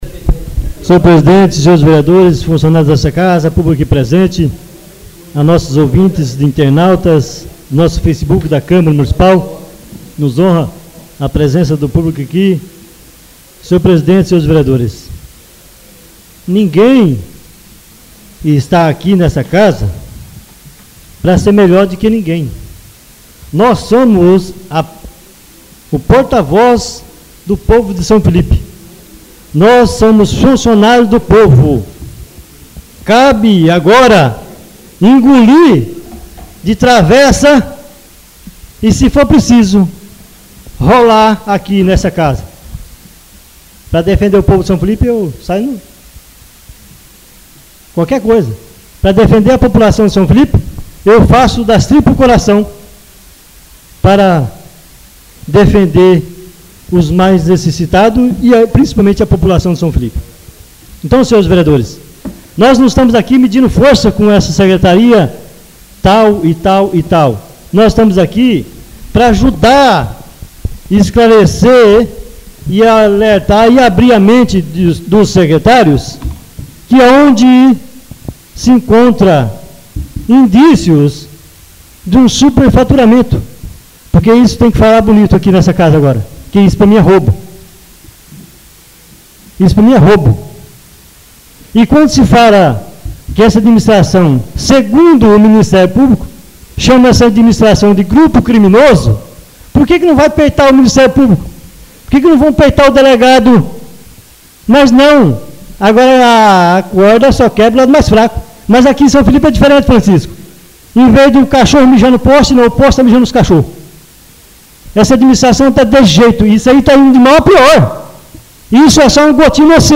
Oradores das Explicações Pessoais (25ª Ordinária da 3ª Sessão Legislativa da 6ª Legislatura)